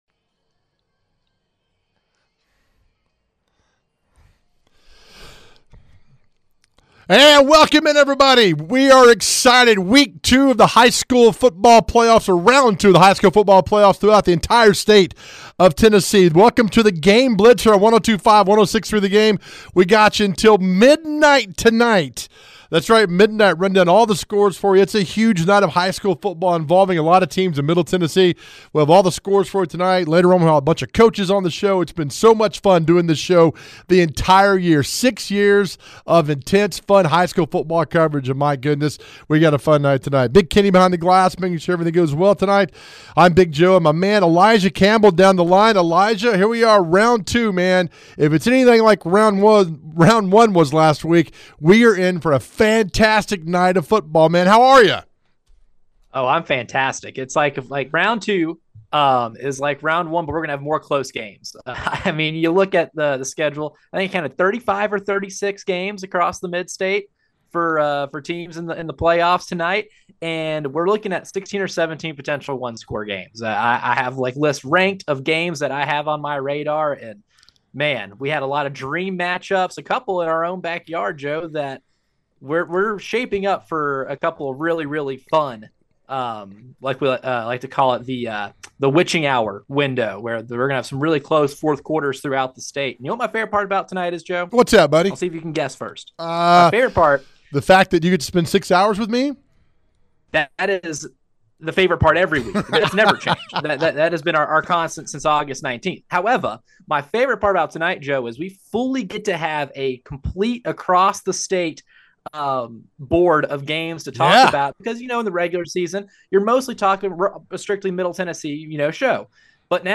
We are in round two of TN High School Football and they cover it all, along with head coach and reporter interviews!